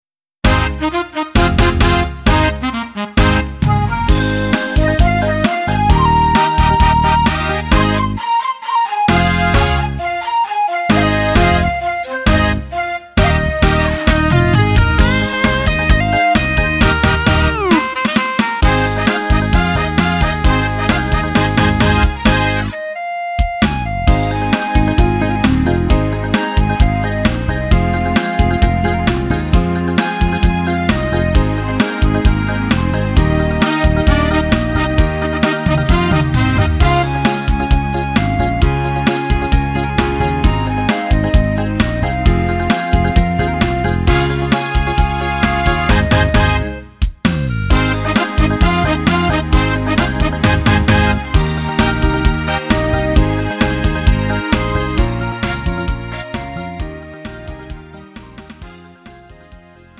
음정 -1키 2:33
장르 가요 구분 Lite MR
Lite MR은 저렴한 가격에 간단한 연습이나 취미용으로 활용할 수 있는 가벼운 반주입니다.